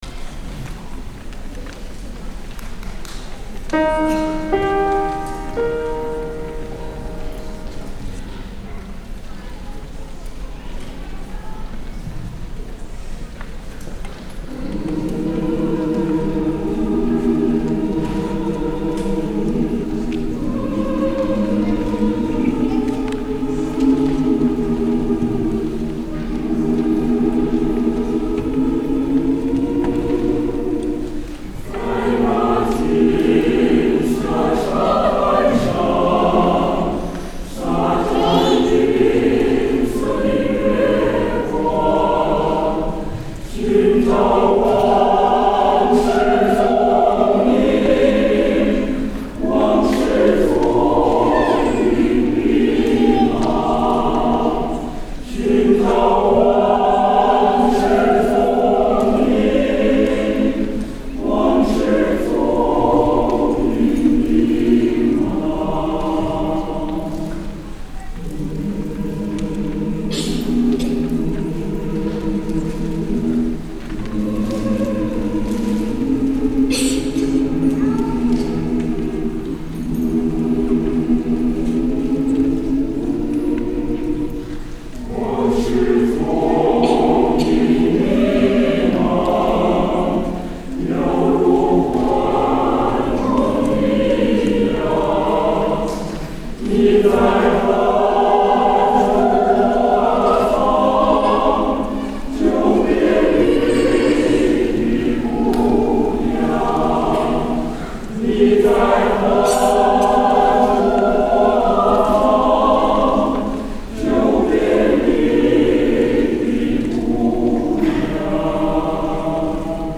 Edison Chinese Chorus - 2004-10-02 Voices from the Homeland @ Nicholas Music Center in New Brunswick, NJ